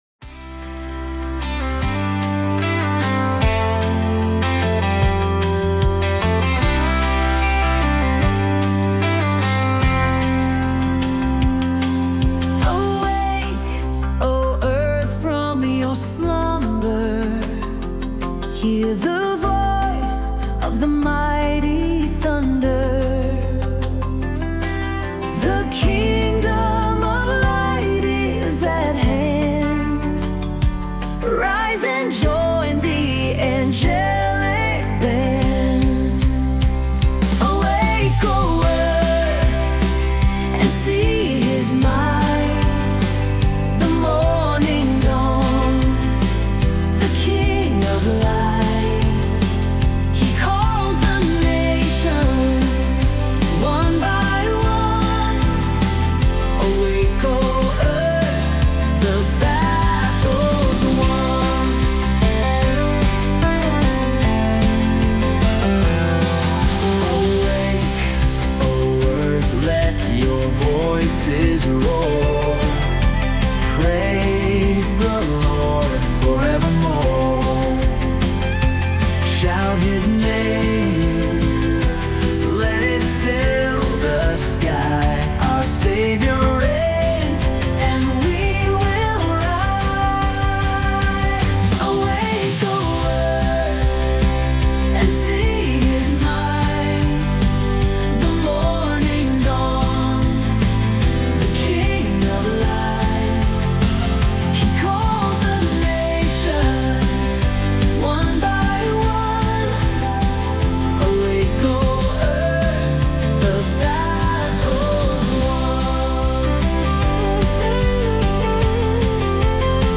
Music
With stirring lyrics and a dynamic melody